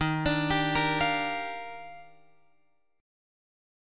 D# Major 9th